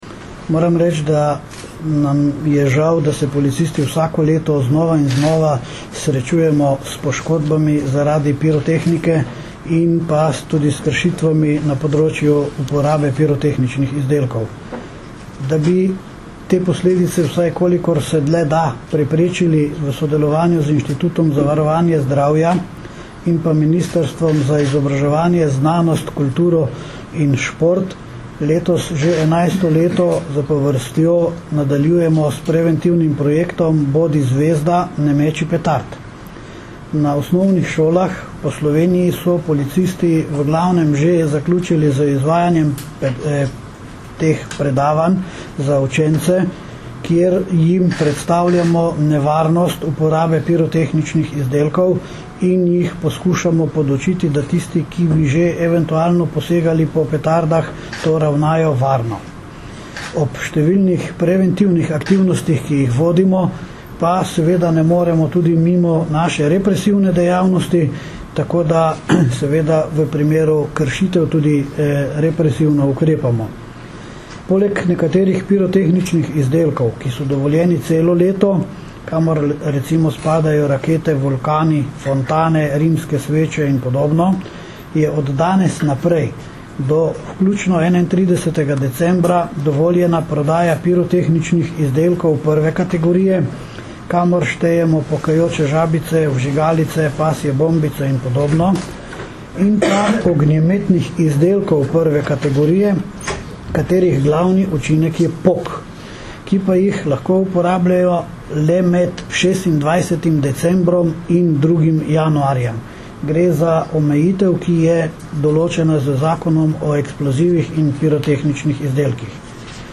Zvočni zapis izjave